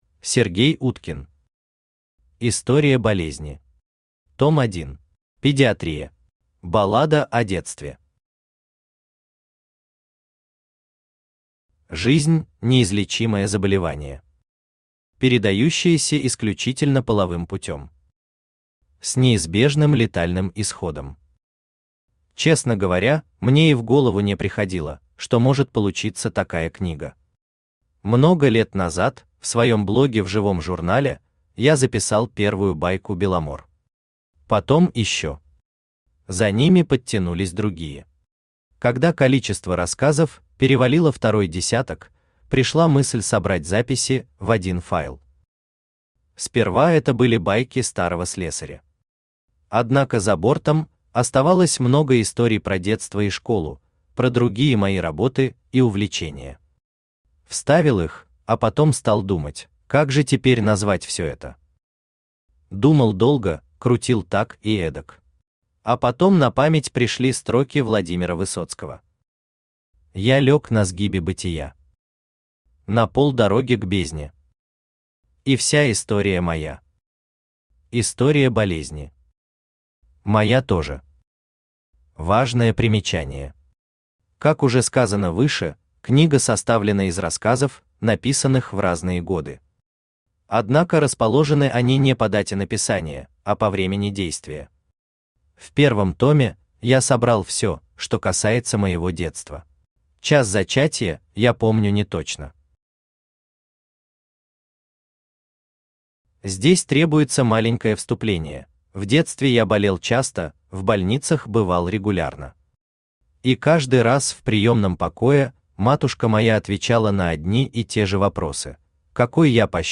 Аудиокнига История болезни. Том 1. Педиатрия | Библиотека аудиокниг
Педиатрия Автор Сергей Валерьевич Уткин Читает аудиокнигу Авточтец ЛитРес.